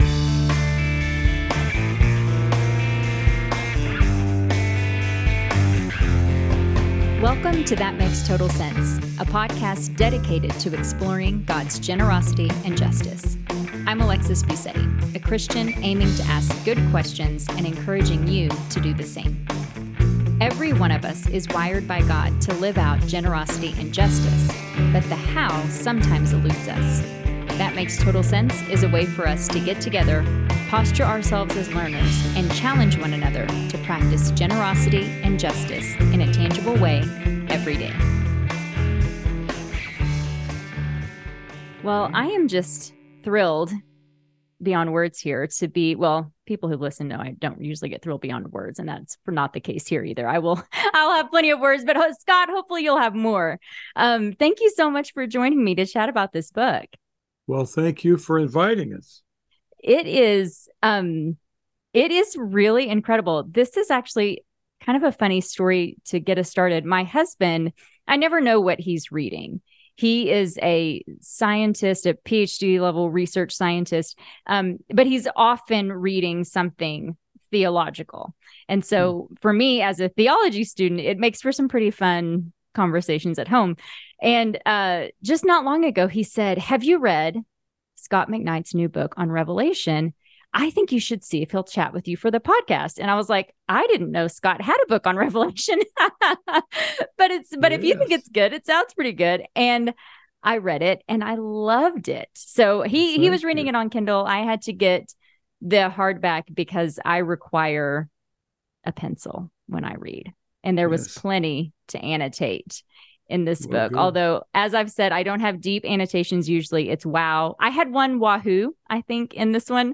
How should we read Revelation? What did the original hearers and readers understand about John's message? Tune in to this conversation to hear a helpful perspective.